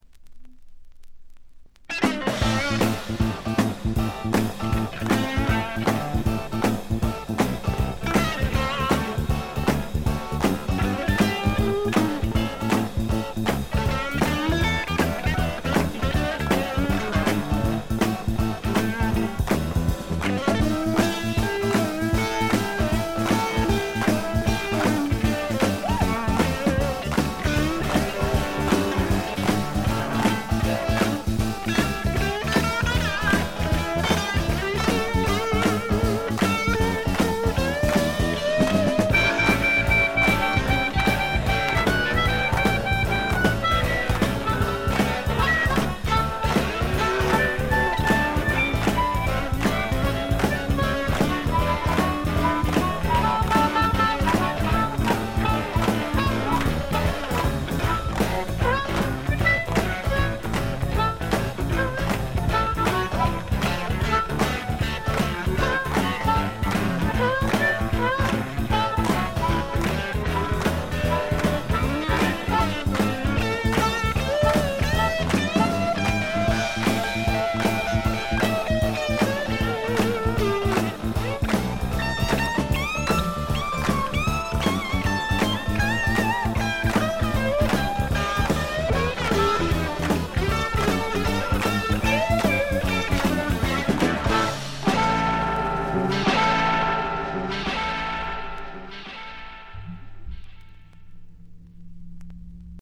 A1中盤とB1序盤でプツ音1回づつ。
よりファンキーに、よりダーティーにきめていて文句無し！
試聴曲は現品からの取り込み音源です。